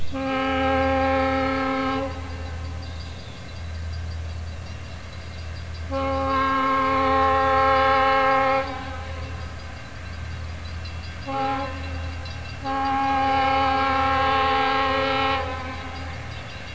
Factory tuning for the M3 was: A major triad (C#,E,A).
Over time as a result of use, C#,D#,A, C#,E,A#, and C#,D#,A# dischords were common.
When bad things happen to good horns:
Otherwise, the horns soon went out of tune.